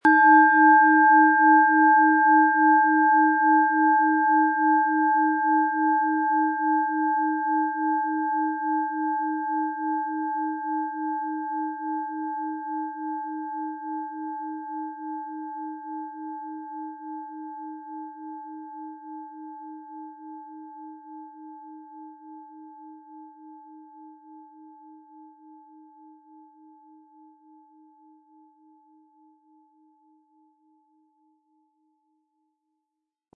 Planetenschale® Herzbereich öffnen & Männlich und Weiblich ausgleichend mit Eros, Ø 16,4 cm, 800-900 Gramm inkl. Klöppel
Planetenton 1
Um den Original-Klang genau dieser Schale zu hören, lassen Sie bitte den hinterlegten Sound abspielen.
PlanetentonEros
SchalenformBihar
MaterialBronze